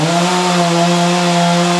Chainsaw Loop.wav